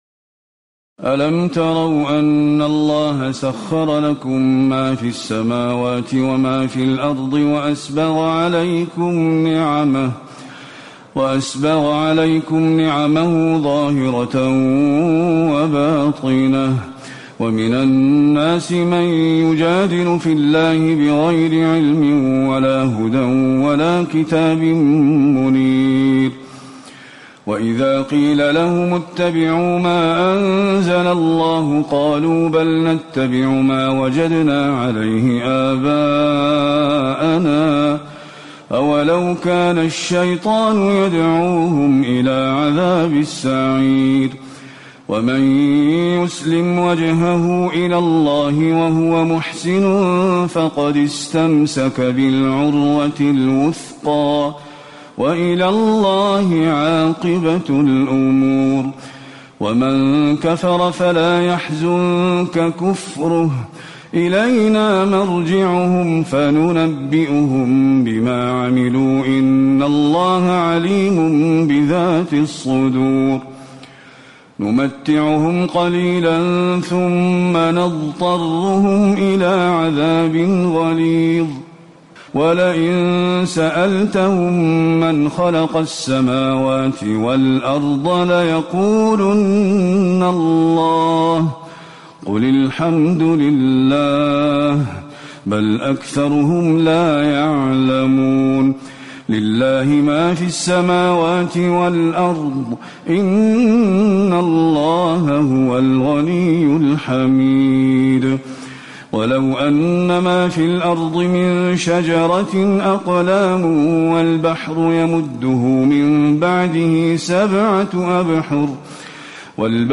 تراويح الليلة العشرون رمضان 1437هـ من سور لقمان (20-34) والسجدة و الأحزاب (1-34) Taraweeh 20 st night Ramadan 1437H from Surah Luqman and As-Sajda and Al-Ahzaab > تراويح الحرم النبوي عام 1437 🕌 > التراويح - تلاوات الحرمين